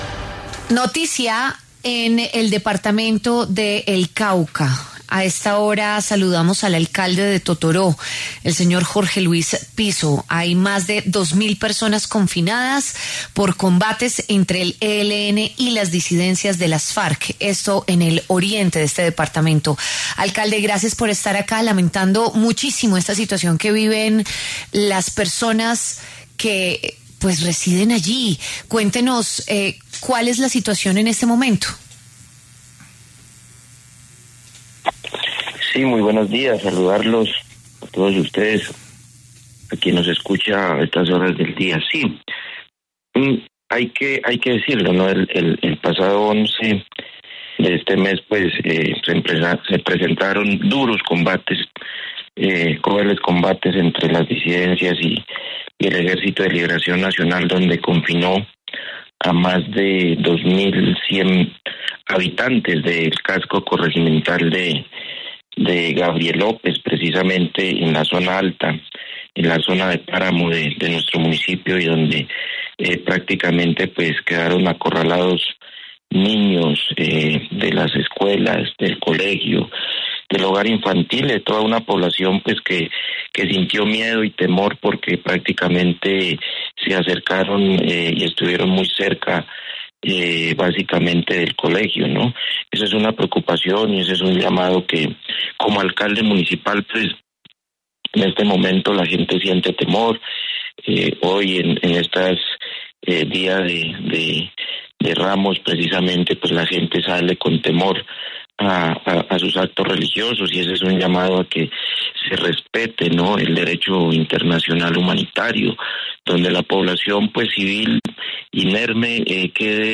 Jorge Luis Pizo, alcalde de Totoró (Cauca), se refirió en W Fin de Semana a las más de dos mil personas que se encuentran confinadas por los combates entre el ELN y las disidencias de las Farc.